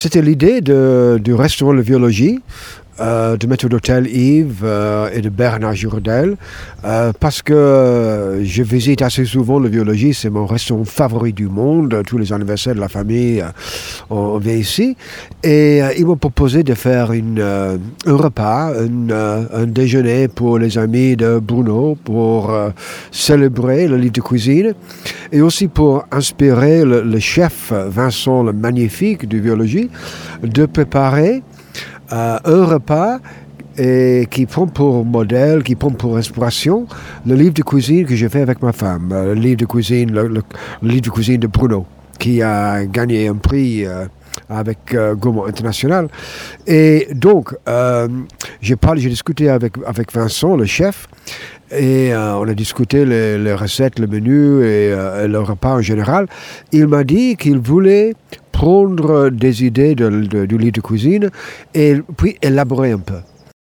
Martin Walker vous en dit plus sur ce déjeuner romanesque…